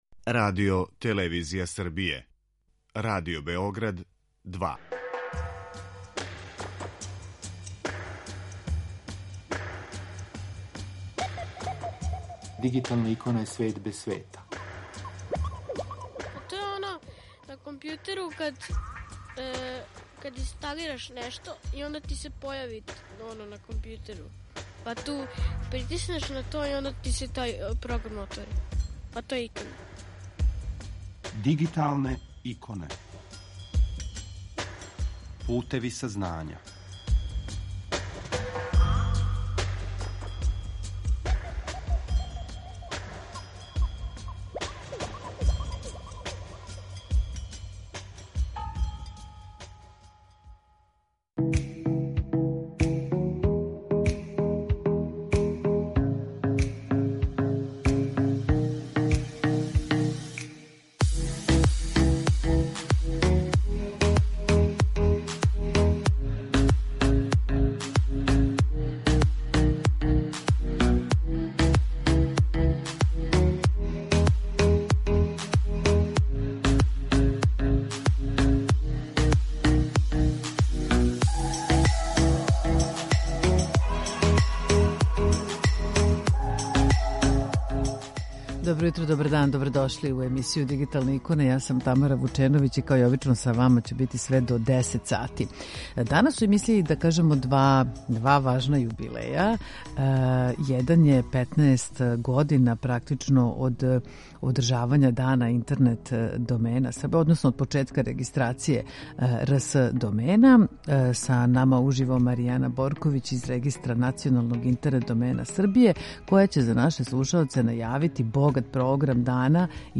На почетку емисије са нама уживо